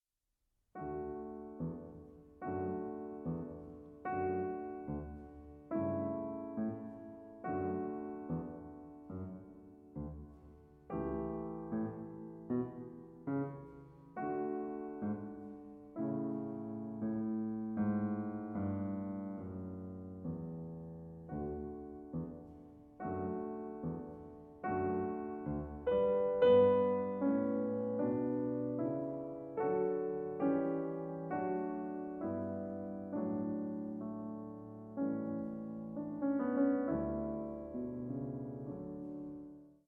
The degree to which he separates the right-hand and left-hand 'characters' of the second movement is exemplary.
PIANO MUSIC